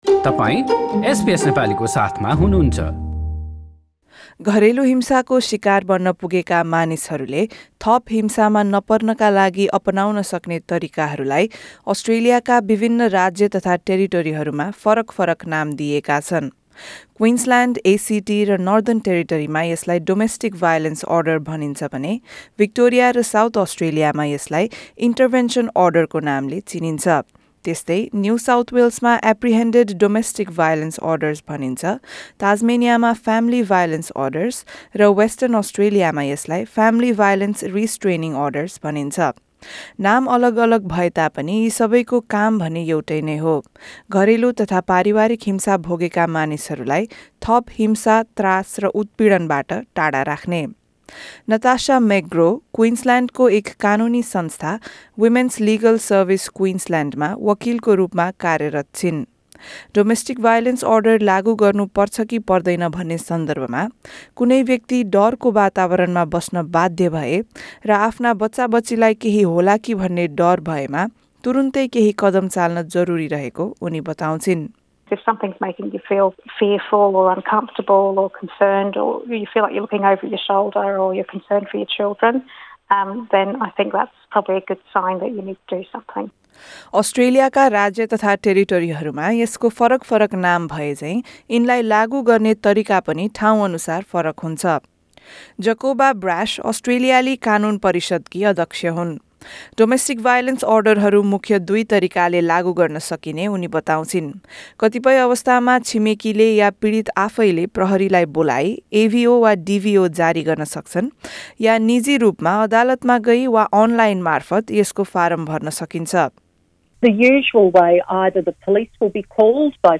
घरेलु हिंसाको सिकार भएका मानिसहरूले थप हिंसाबाट आफूलाई जोगाउनका लागि इन्टरभेन्सन अर्डर लगायत अन्य तरिकाहरू अपनाउन सक्छन्। यी तरिकाहरू केके हुन्, कसरी लागु गर्न सकिन्छ, र तिनले कसरी मद्दत गर्छन् भन्ने बारेमा एक रिपोर्ट।